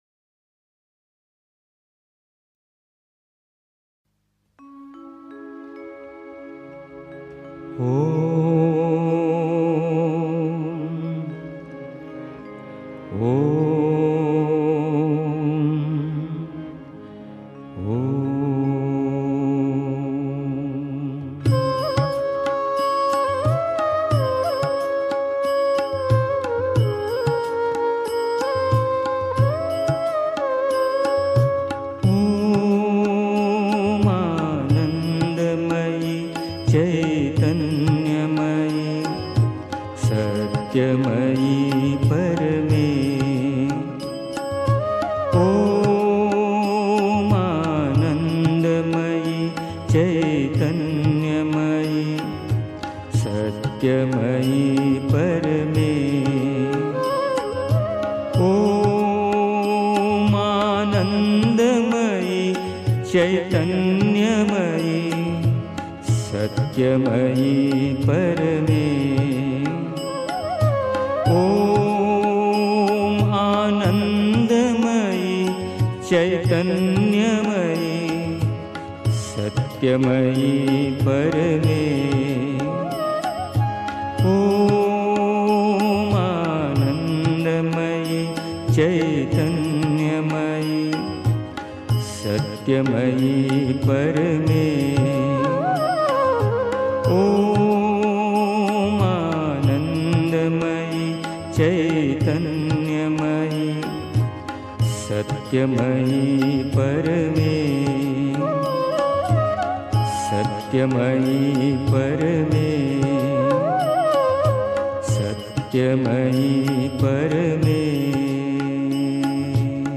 1. Einstimmung mit Musik. 2. Wahrhaft wollen können (Die Mutter, The Sunlit Path) 3. Zwölf Minuten Stille.